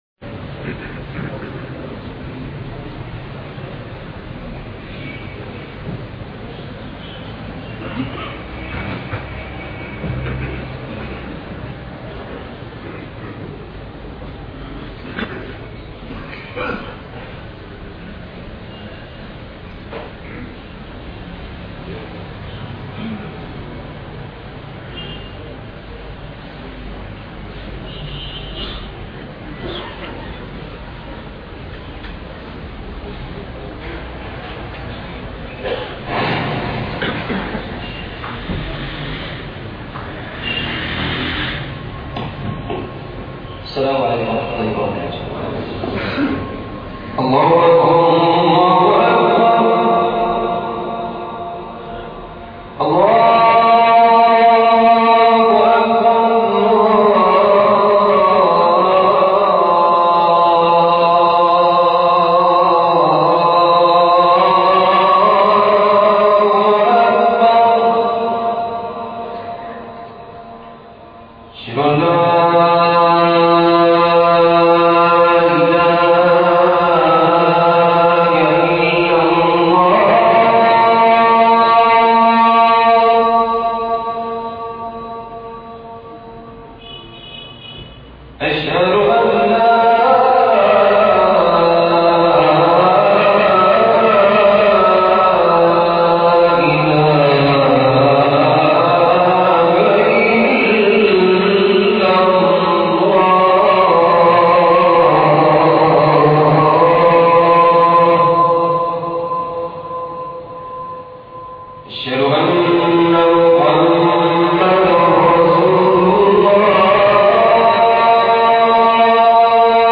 الشباب والمظاهرات خطبة(4/2/2011